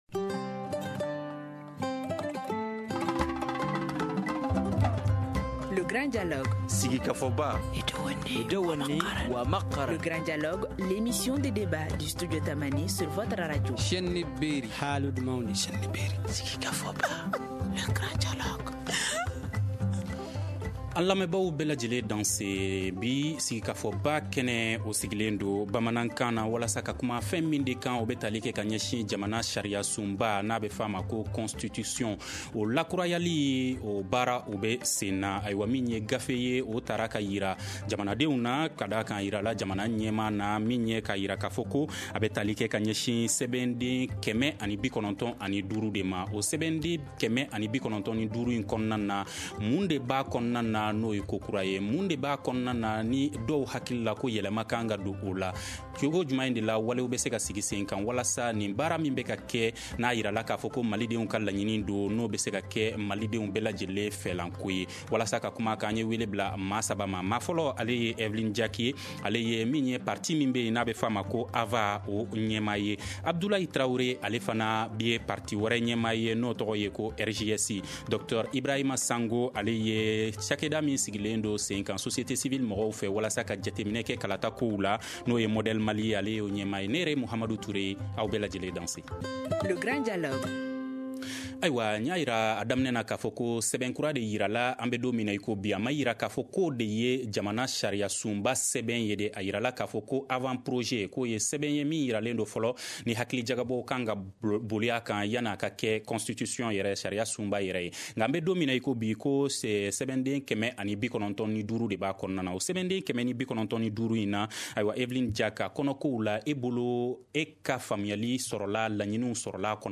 Le Grand Dialogue de Studio Tamani pose le débat avec